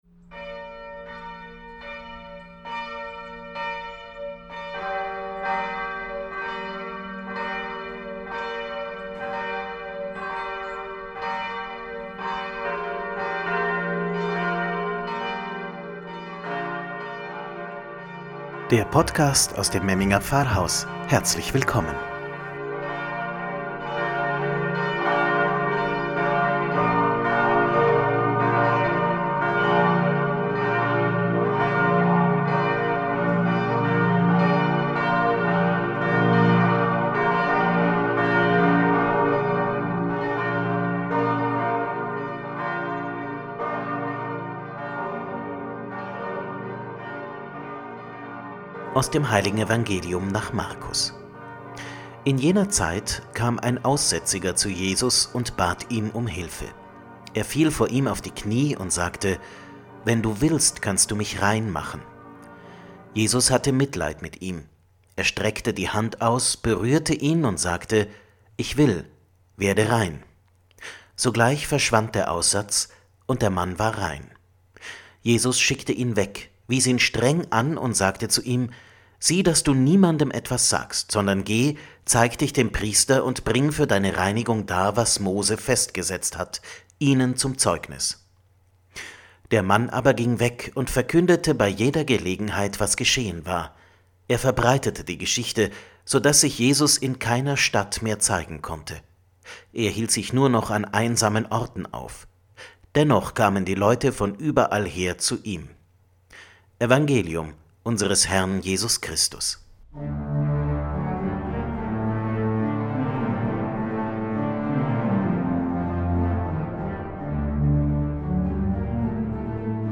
„Wort zum Sonntag“ aus dem Memminger Pfarrhaus – Sechster Sonntag im Jahreskreis 2021 Faschingssonntag